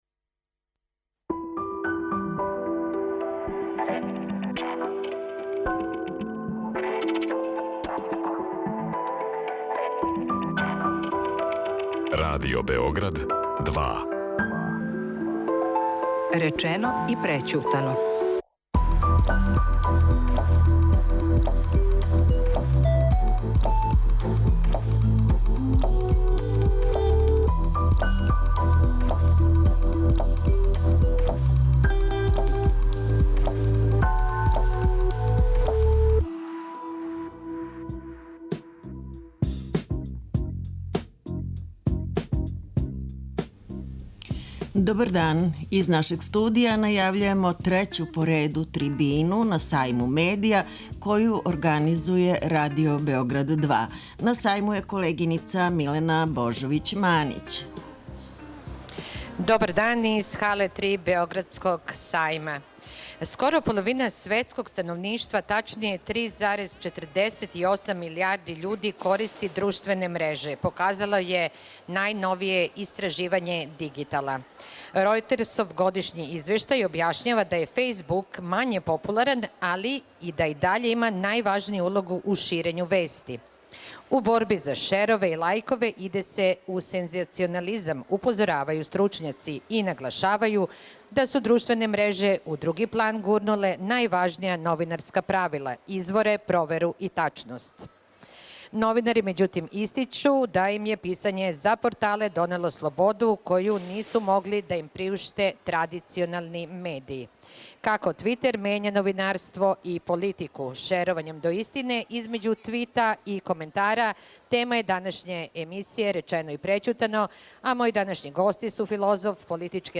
Радио Београд 2 на Сајму медија